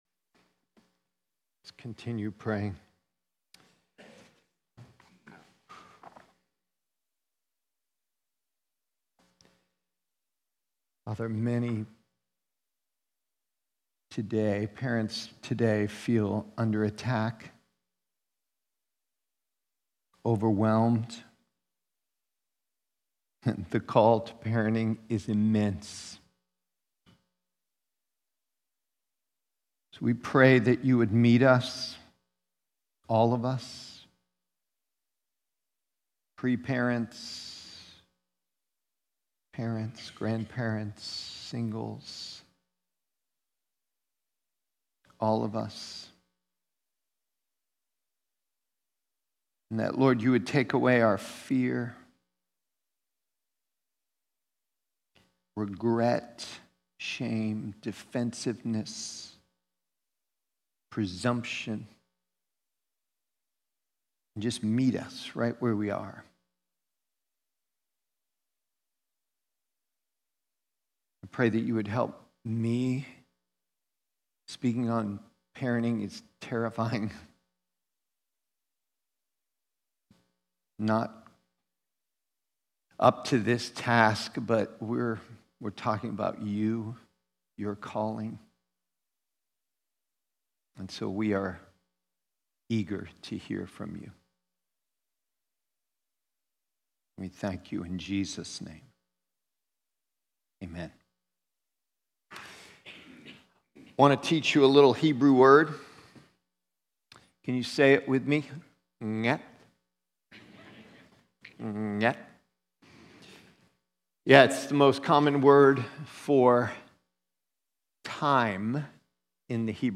Prev Previous Sermon Next Sermon Next Title Seasons of Christian Parenting